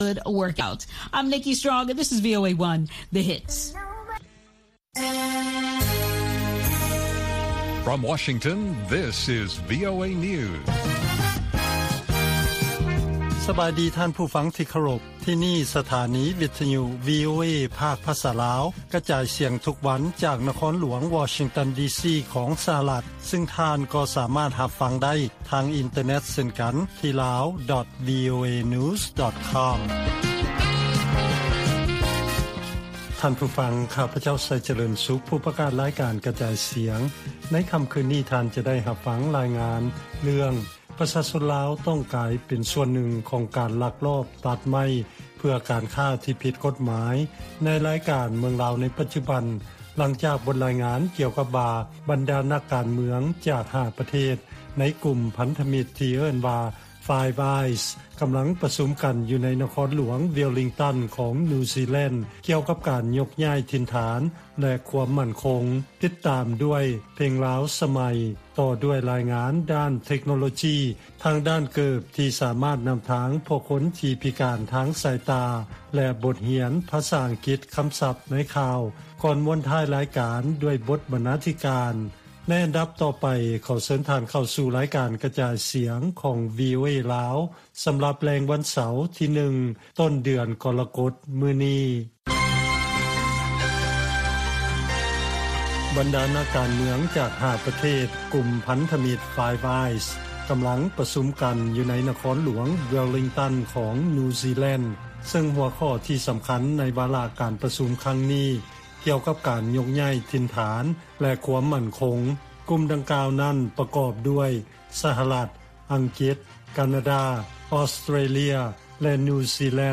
ລາຍການກະຈາຍສຽງຂອງວີໂອເອ ລາວ: ຄູ່ຮ່ວມທາງດ້ານຄວາມໝັ້ນຄົງ Five Eyes ຈັດການປະຊຸມທີ່ປະເທດນິວຊີແລນ